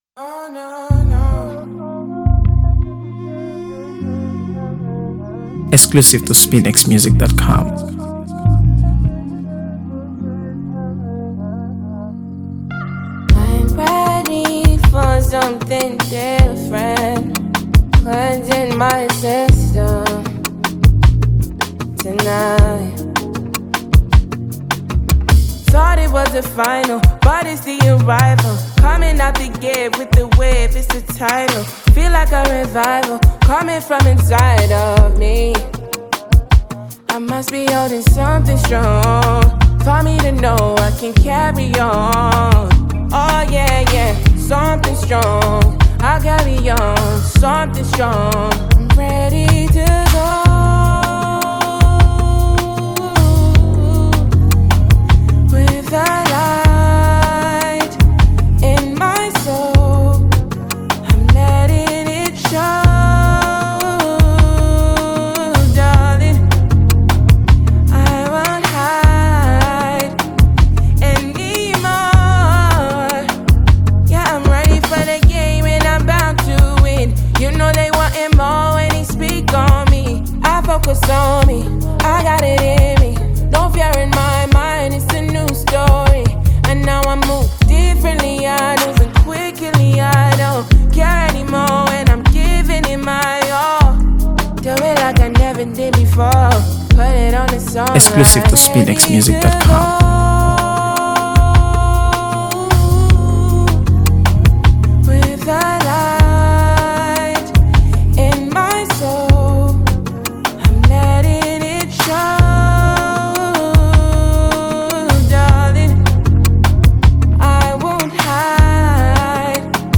AfroBeats | AfroBeats songs
Nigerian singer-songwriter